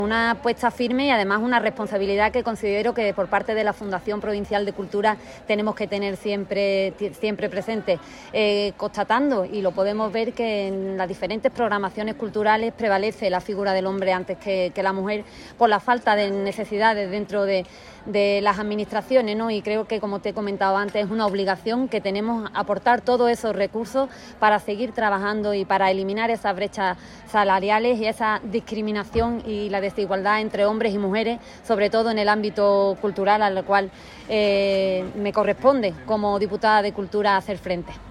Vanesa Beltrán inaugura la primera cita del Festival Caleidoscópicas que llegará en las próximas semanas a distintas localidades de la provincia
Inauguracion-Caleidoscopicas_Vanesa-Beltran.mp3